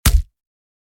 Hit_Enemy2.wav